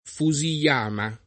Fujiyama [giapp. PuJiL#ma] top. m. (Giapp.) — forma, non corretta in giapp., con cui in Occidente si designa il monte chiamato propr. Fujisan [P2JiSan] o assol. Fuji [P2Ji] — usata a volte in It. (ma non bene) anche la forma Fusiyama [giapp. PuJiL#ma; italianizz.